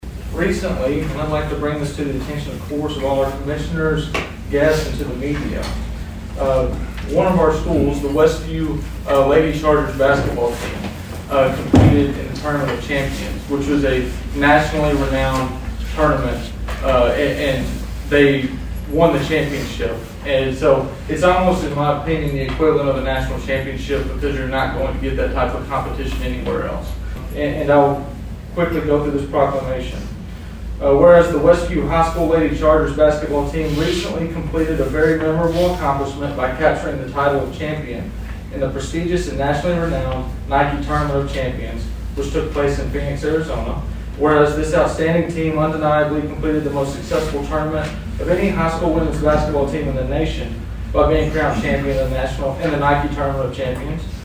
During the monthly meeting of the Weakley County Commission on Tuesday, County Mayor Dale Hutcherson issued a proclamation celebrating the Westview Lady Chargers for their victory at the Nike Tournament of Champions in December.